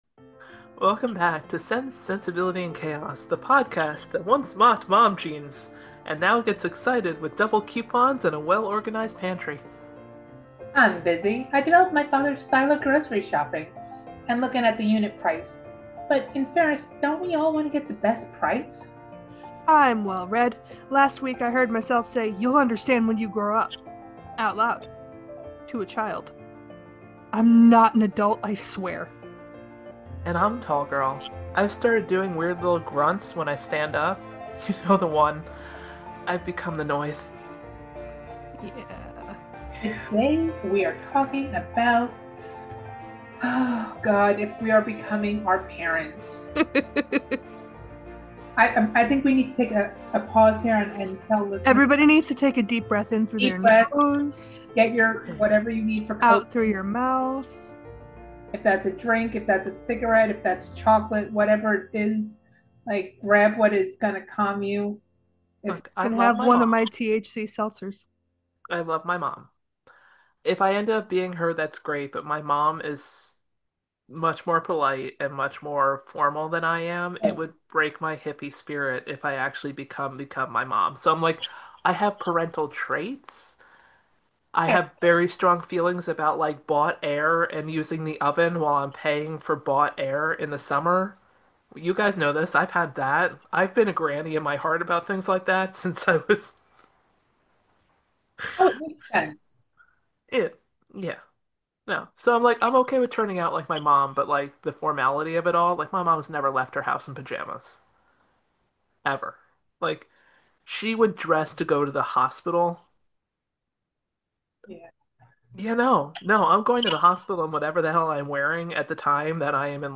Welcome to Sense, Sensibility, and Chaos, a podcast where three friends with strong opinions and very different personalities take on the world in one slightly unhinged conversation at a time.